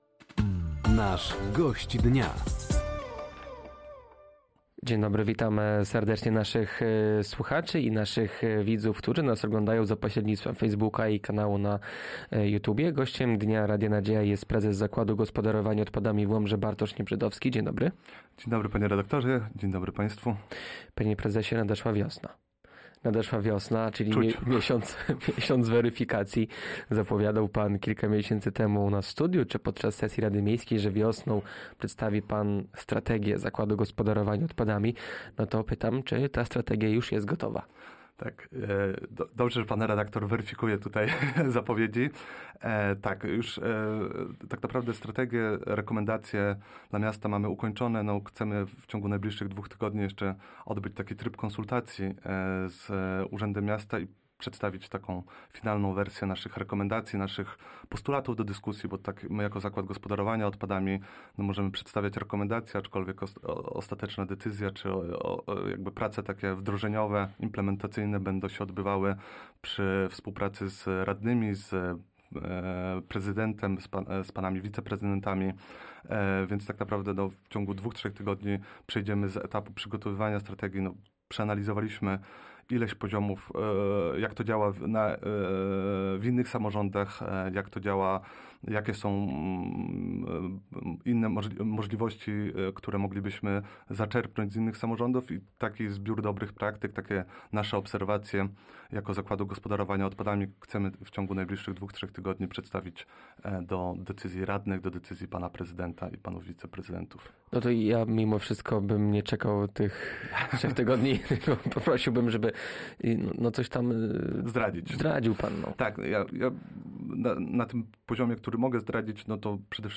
Strategia ZGO, uszczelnianie systemu śmieciowego, a także segregowanie odpadów – to główne tematy rozmowy z Gościem Dnia Radia Nadzieja.